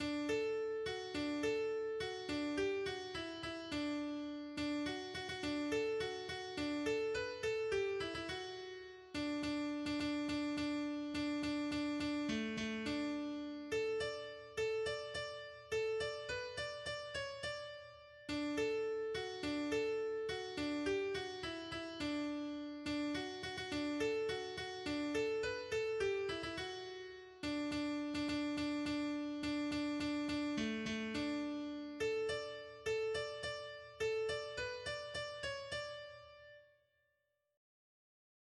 “To Portsmouth,” twice through, on a synthesized grand piano, in D.